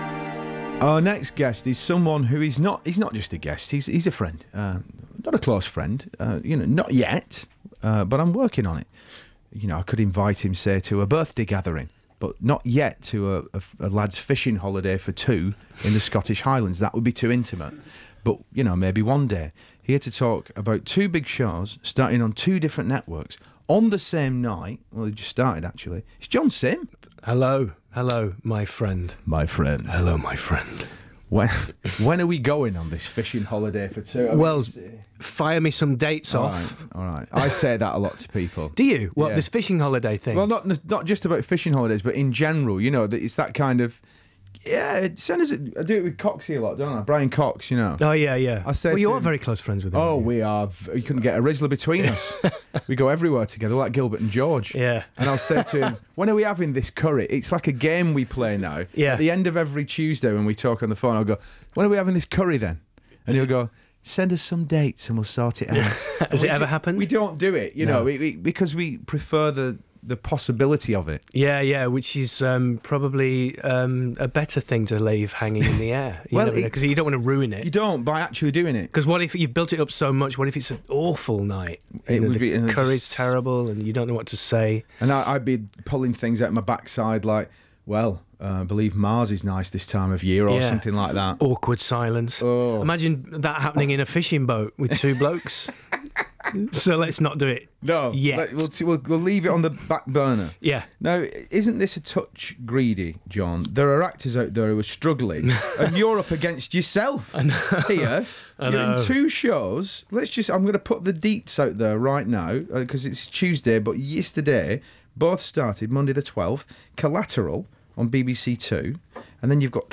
BBC Radio 6 Music Shaun Keaveny 13 Feb 2018 John Simm joins Shaun to chat about his two new dramas premiering on the same night and time: Trauma on ITV and Collateral on BBC Two.